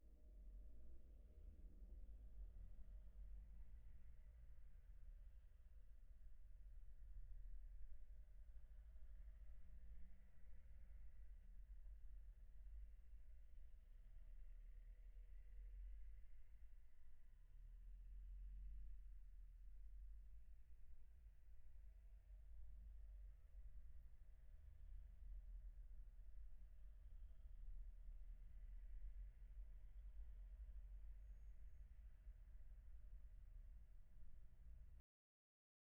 This test uses audio samples taken from three everyday scenarios: a busy street, an office, and an airplane cabin.
Street noise recordings.
street-noise.wav